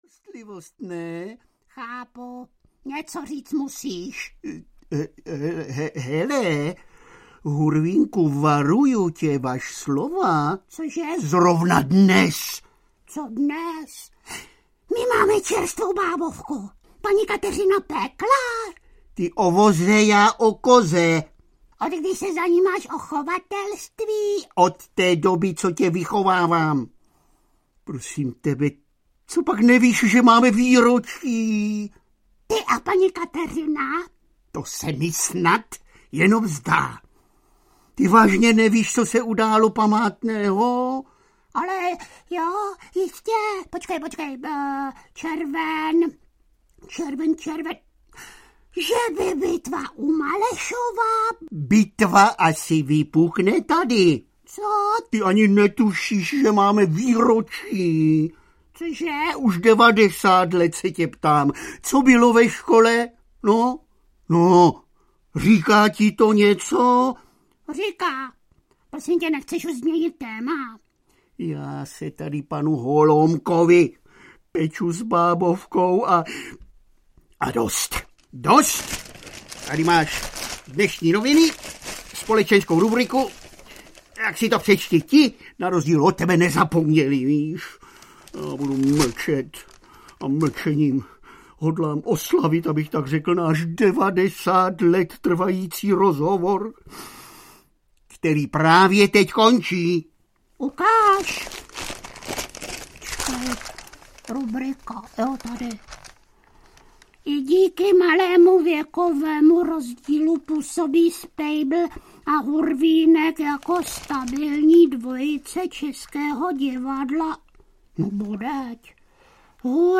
Ukázka z knihy
Je to velmi zajímavá exkurze do slavné historie divadla S + H, ale také zvukových záznamů v českém jazyce, třeba ještě zprvu točených ve studiu v Berlíně.
Aktuální vstupní dialog k obsahu tohoto mimořádného albumu letos v únoru Spejbl a Hurvínek natočili jako vtipnou pozvánku a osobní vysvětlivku.